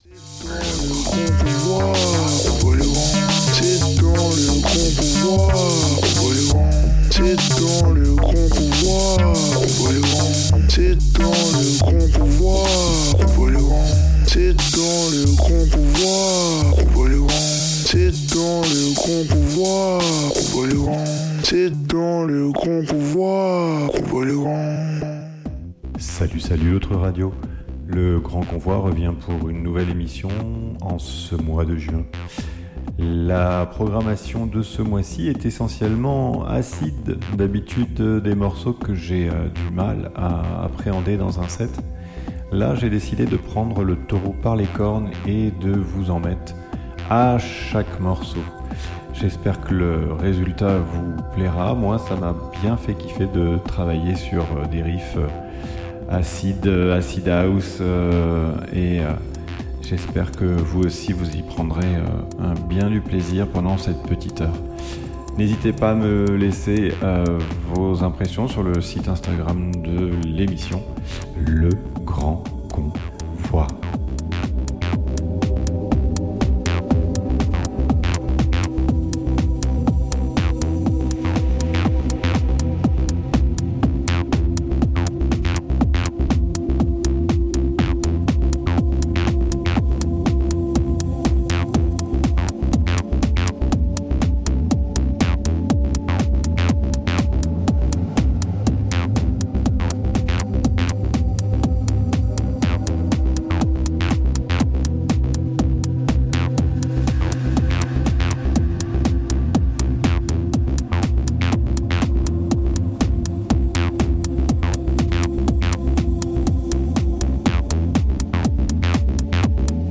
Un set mensuel d'une heure